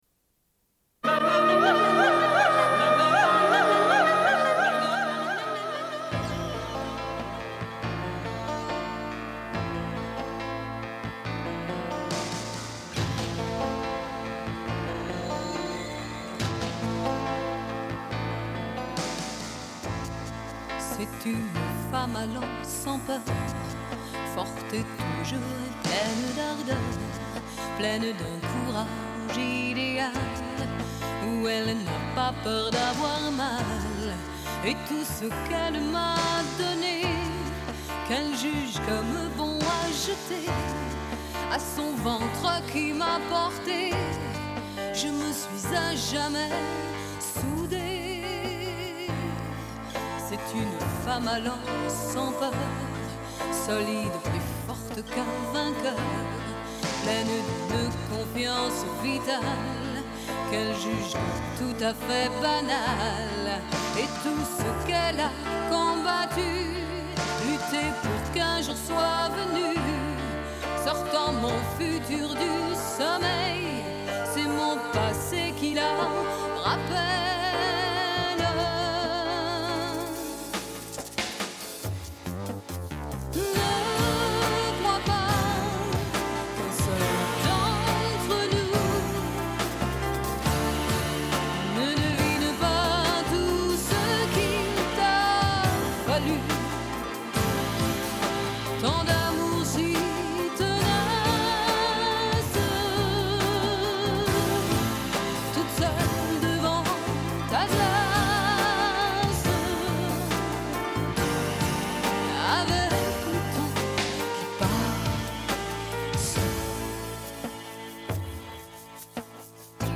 Une cassette audio, face B
Radio